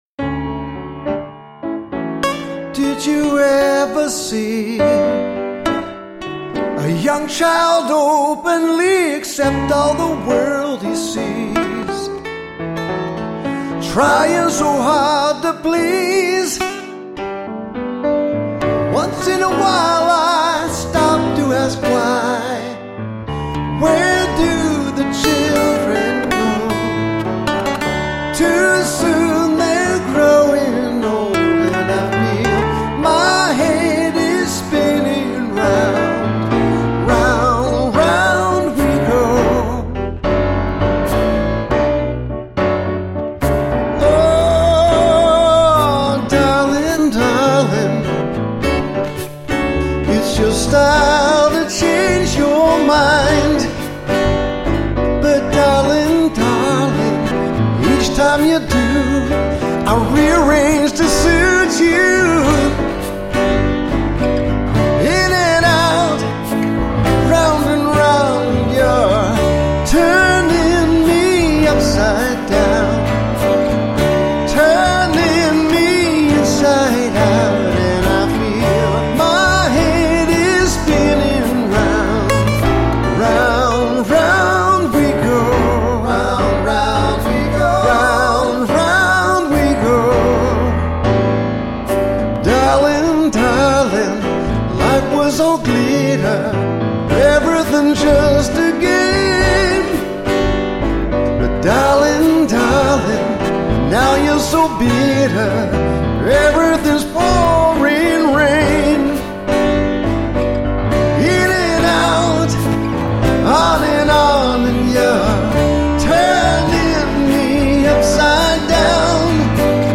notice, no electronic drums on this version (thank heavens!)